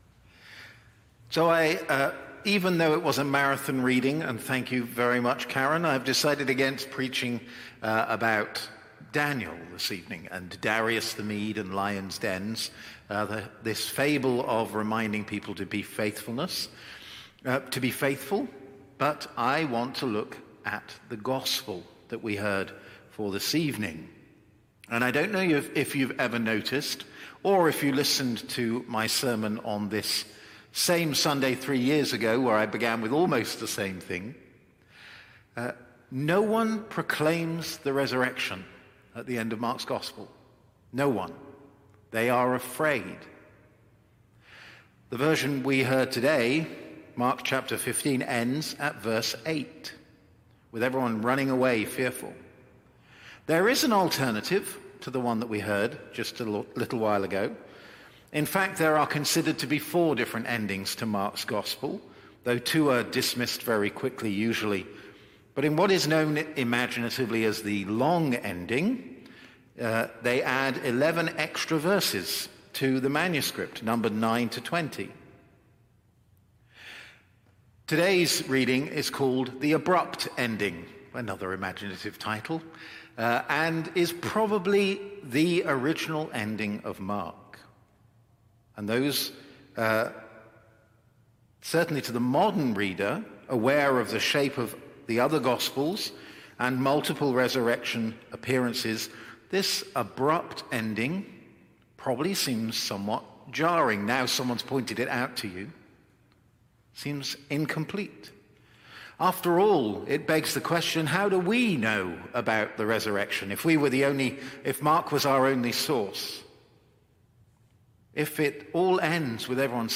Sermons | St. John the Divine Anglican Church
Evensong Reflection